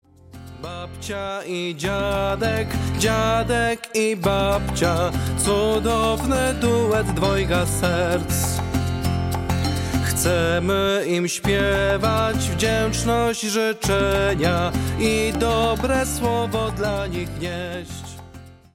Autorska piosenka, idealna na Dzień Babci i Dziadka.